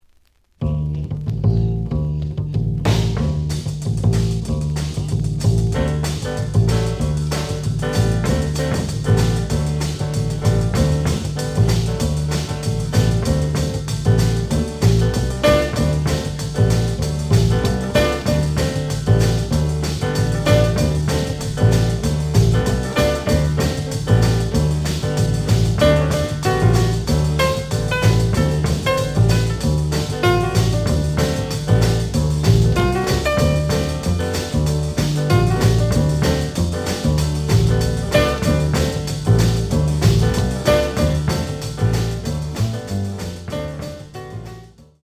The audio sample is recorded from the actual item.
●Genre: Jazz Funk / Soul Jazz
Slight edge warp. But doesn't affect playing. Plays good.